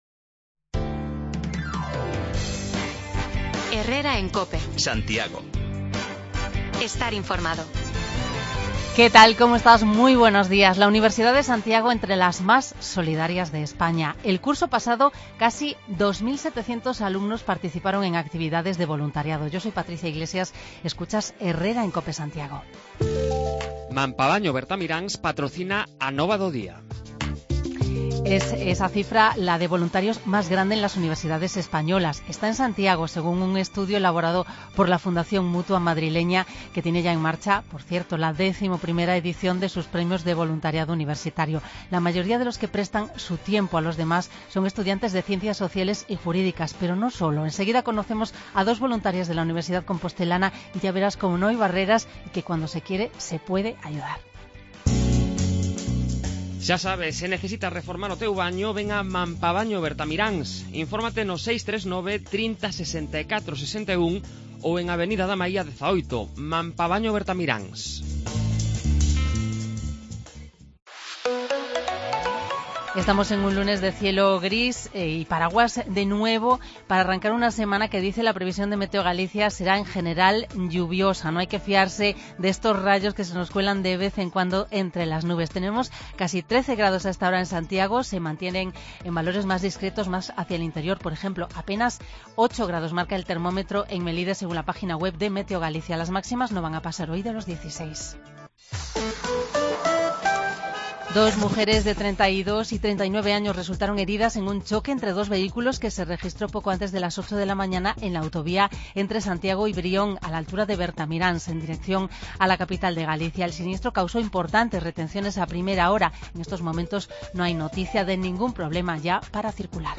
Nos visitan en la radio dos voluntarias en los programas de voluntariado de la USC